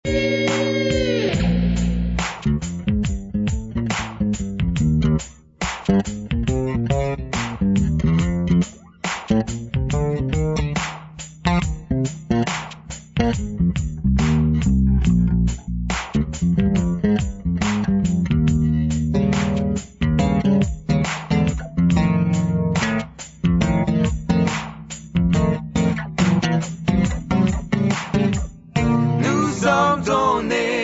Extrait de solo avec résolution par un jeu en accord